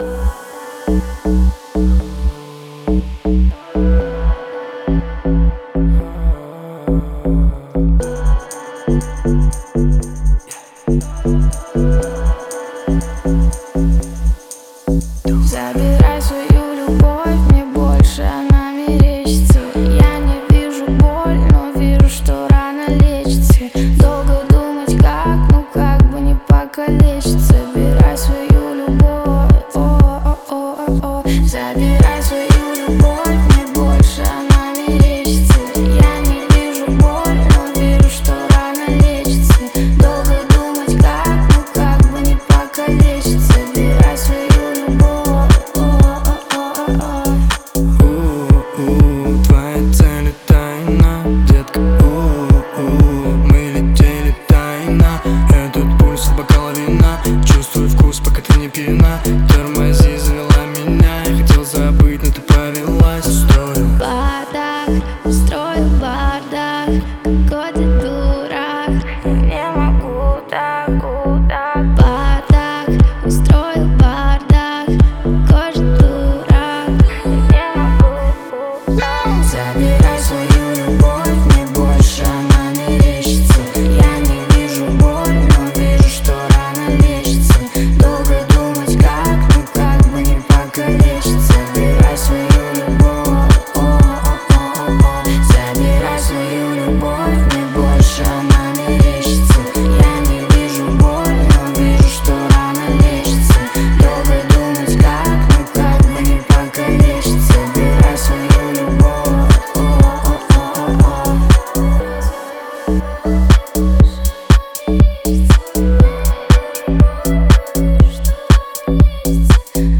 яркая поп-музыка